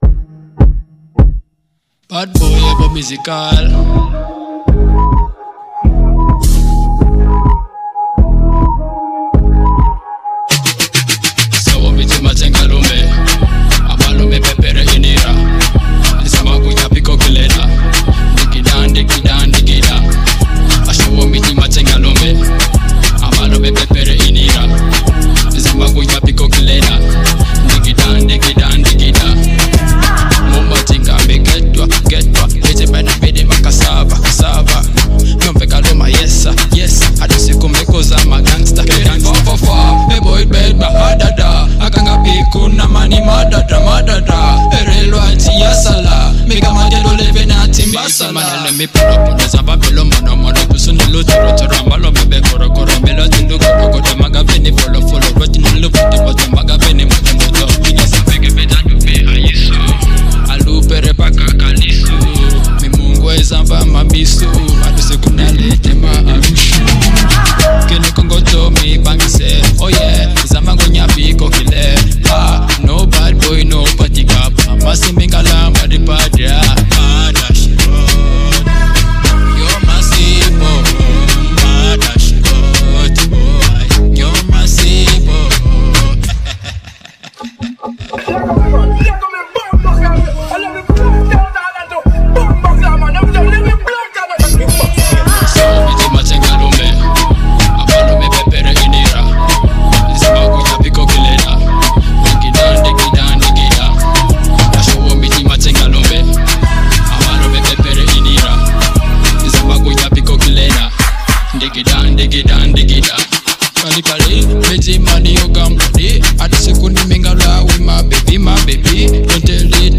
Genre: Luga Flow